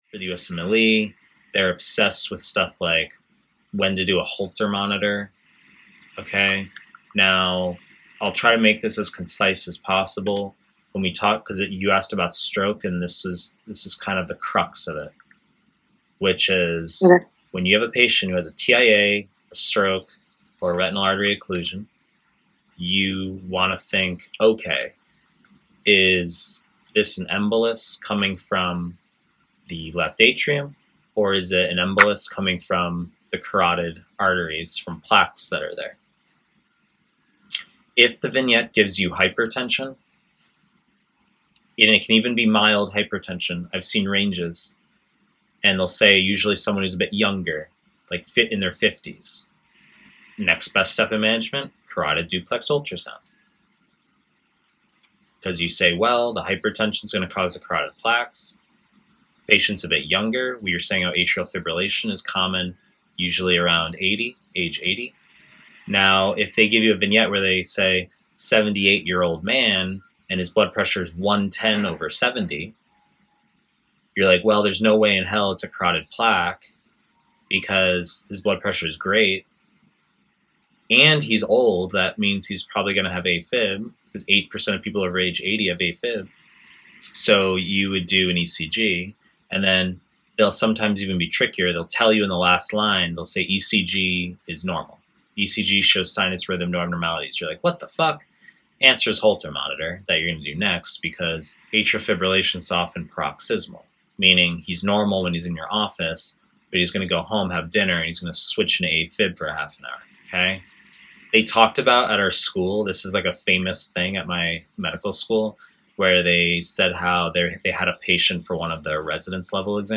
Cardio / Internal Medicine / Pre-recorded lectures